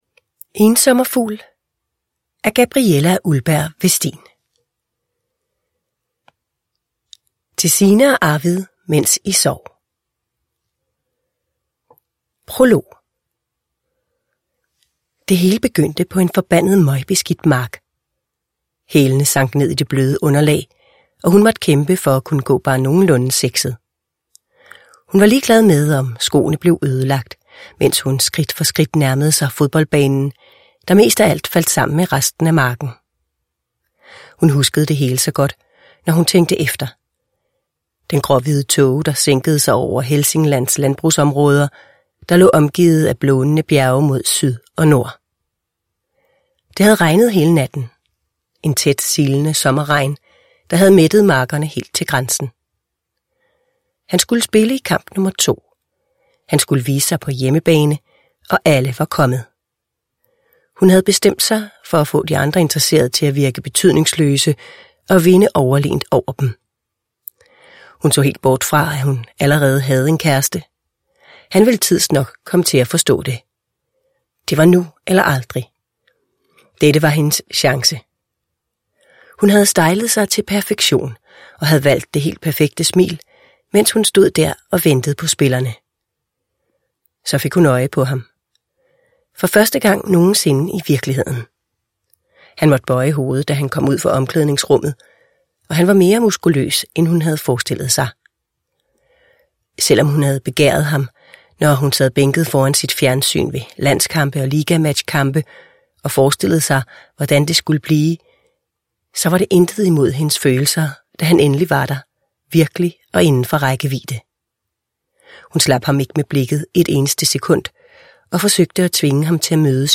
Ensommerfugl – Ljudbok – Laddas ner